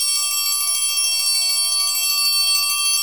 Index of /90_sSampleCDs/Roland LCDP03 Orchestral Perc/PRC_Orch Toys/PRC_Orch Triangl